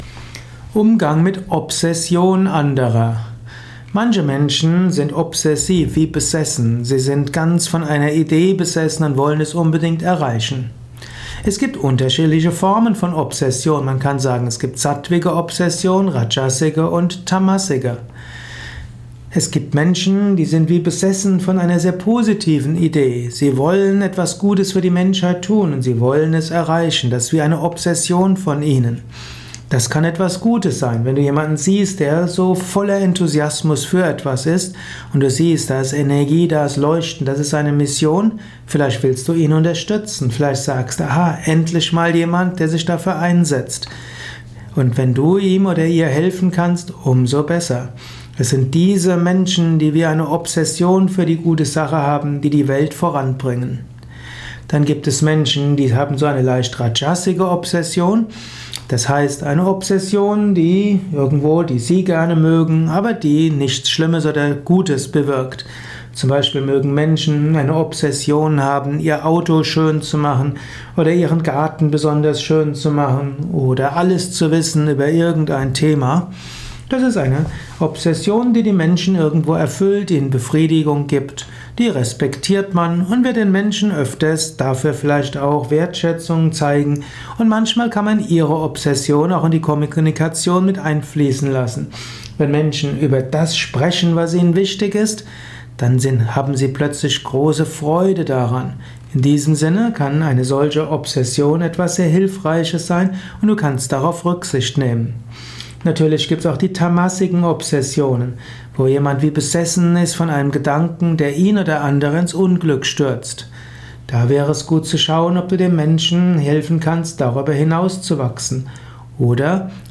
Inspirierender Abhandlung über das Thema Obsession anderer. Informationen und Anregungen zum Wort bzw. Ausdruck Obsession in einem besonderen Spontan-Audiovortrag.